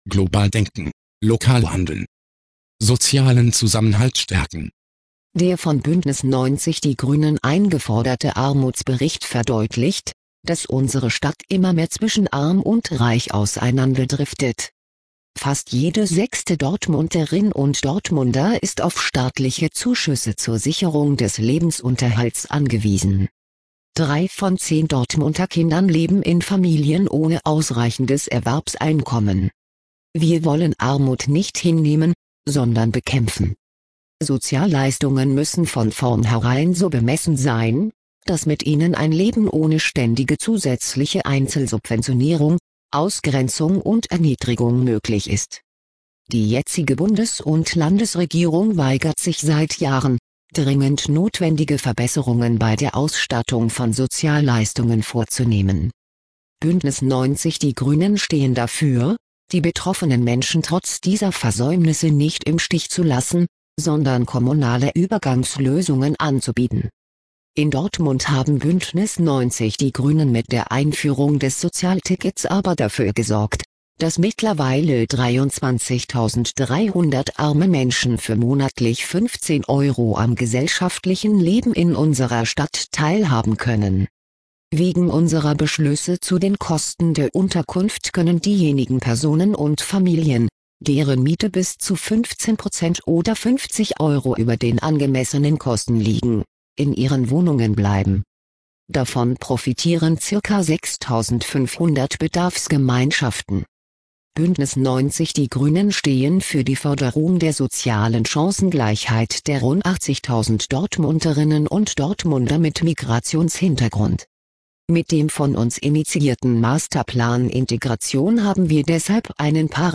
Auszüge aus unserem Kommunalwahlprogramm 2009 als Sprachversion